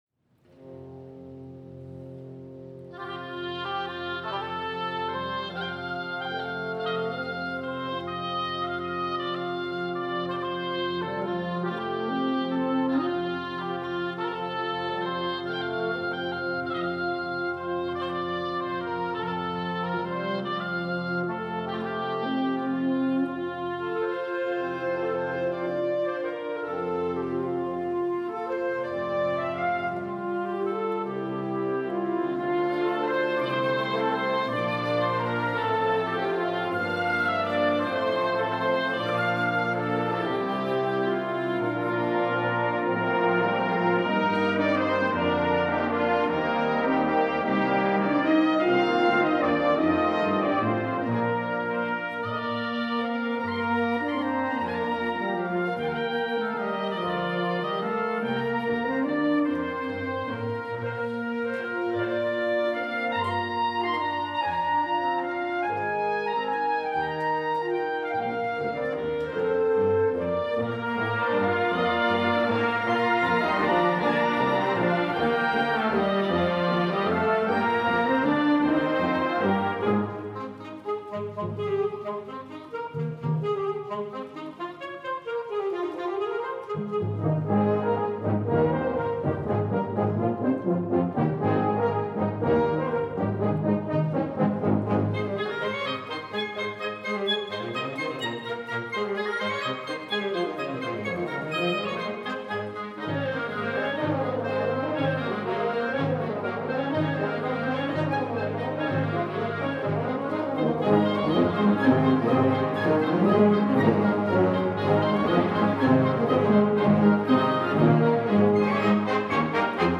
編成：吹奏楽